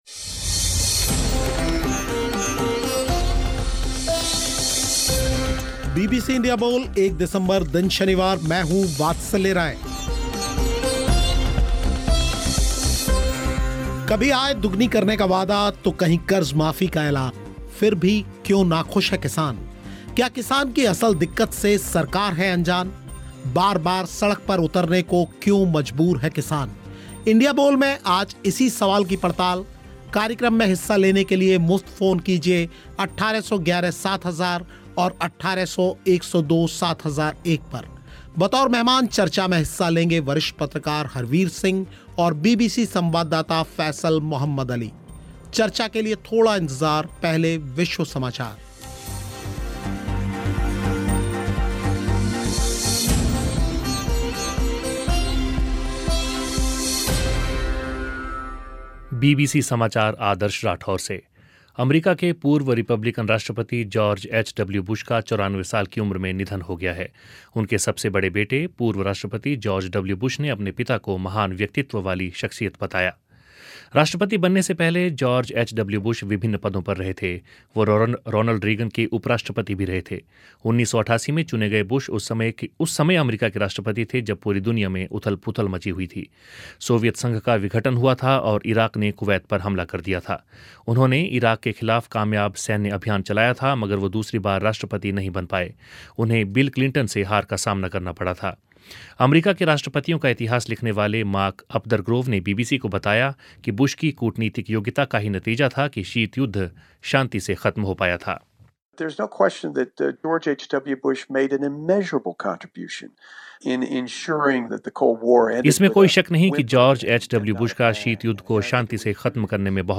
Headliner Embed Embed code See more options Share Facebook X कभी आय दुगनी करने का वादा तो कहीं कर्ज़ माफ़ी का ऐलान फिर भी क्यों नाखुश हैं किसान क्या किसान की असल दिक्कत से सरकार है अनजान बार-बार सड़क पर उतरने को क्यों मजबूर हैं किसान? इंडिया बोल में आज इसी सवाल पर हुई चर्चा
श्रोताओं ने भी रखी अपनी बात